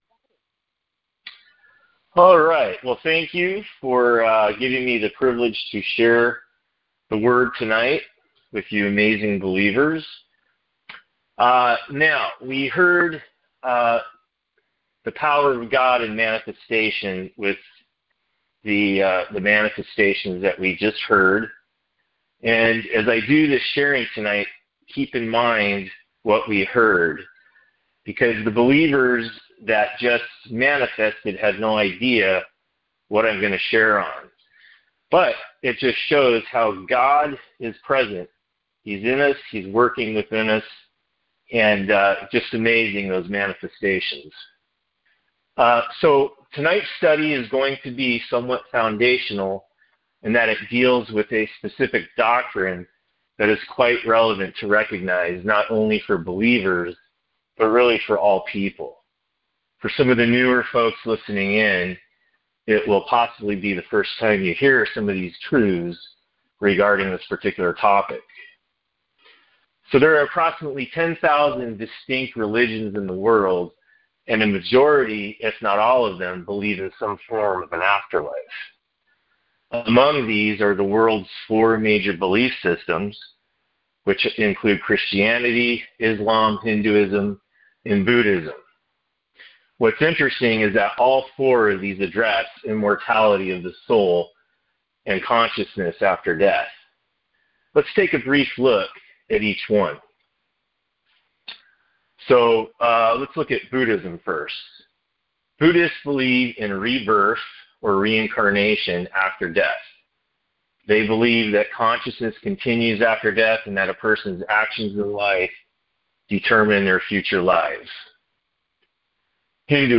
Details Series: Conference Call Fellowship Date: Wednesday, 26 February 2025 Hits: 360 Scripture: 1 Thessalonians 4:13-18 Play the sermon Download Audio ( 15.18 MB )